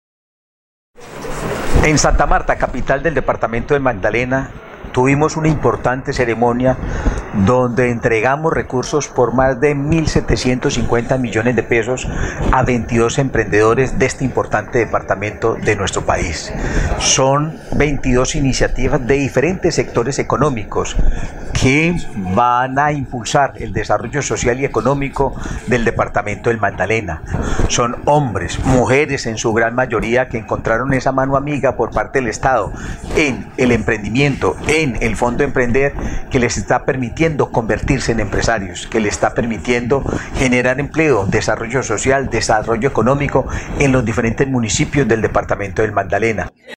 Testimonio-del-director-general-del-SENA-Carlos-Mario-Estrada-Molina-online-audio-converter.com_.mp3